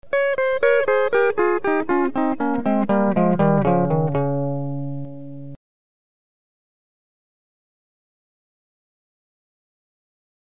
The delay effect combines the unprocessed signal with a delayed copy of itself.
500 ms delay
delay_500ms
delay500ms.mp3